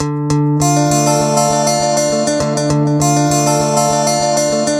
描述：国家/地区和民族|欢快
标签： 贝司 电吉他 原声吉他
声道立体声